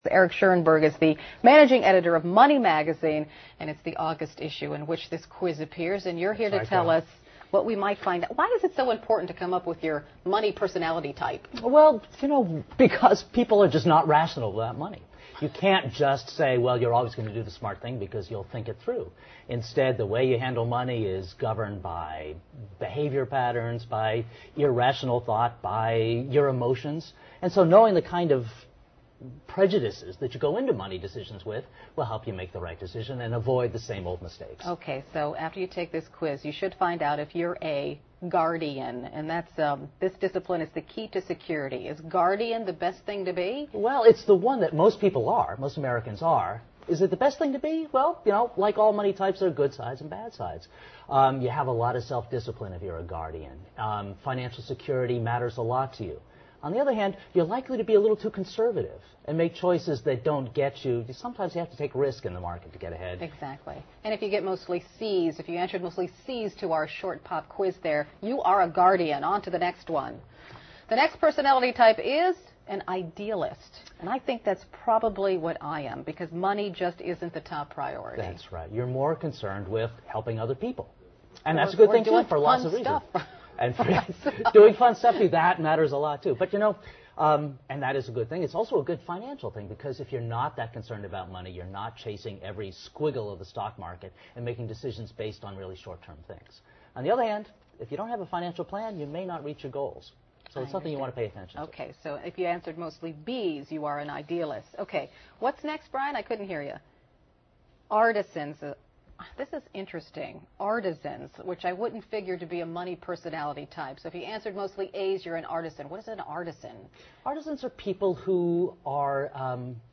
在线英语听力室你的理财方式属于哪种?的听力文件下载,访谈录-在线英语听力室